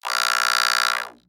robotscream_9.ogg